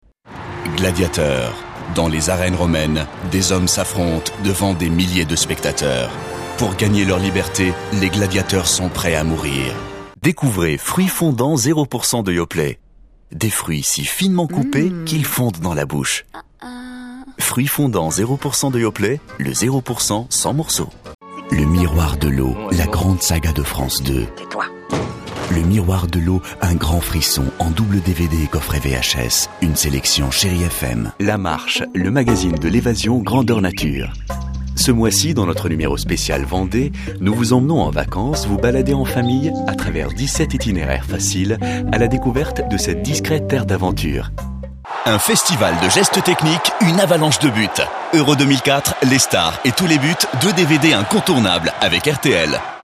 Arabic, Lebanese / French. Voice-over, mid-range, versatile.
Arabic (Lebanese) Commercial Mix